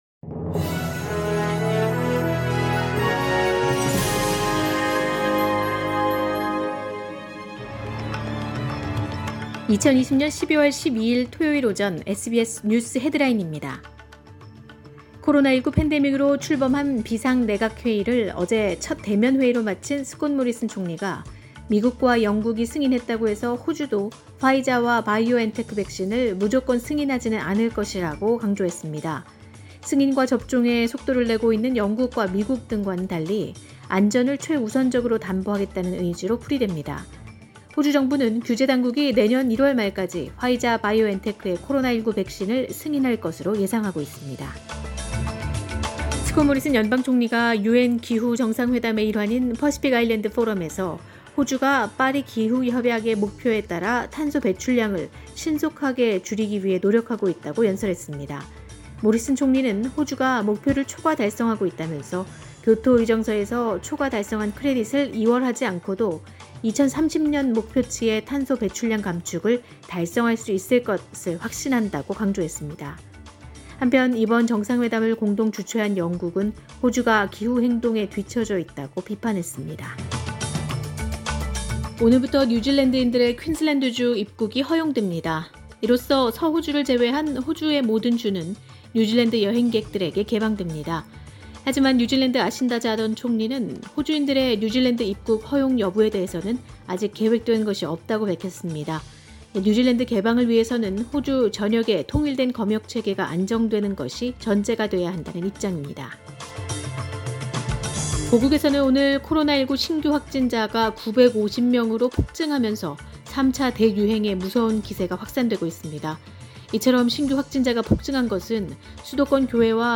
2020년 12월 12일 토요일 오전의 SBS 뉴스 헤드라인입니다.